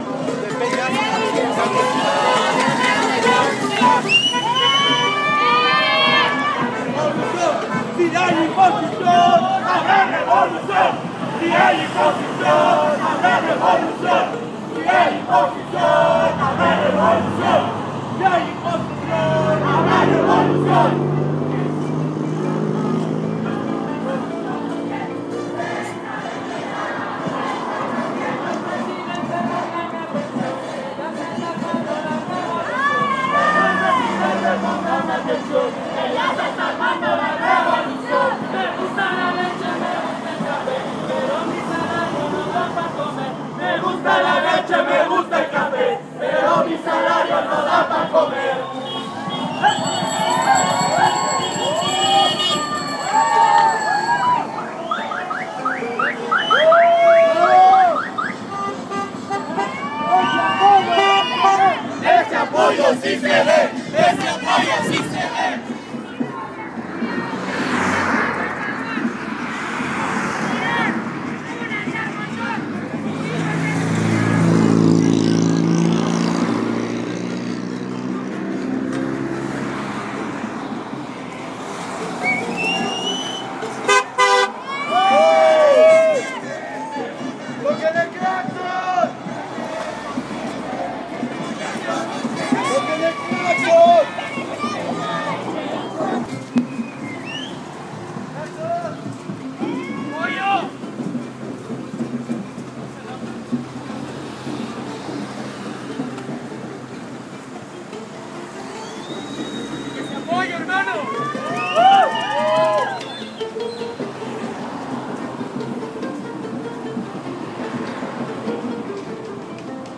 Sonidos de marcha AntiPeña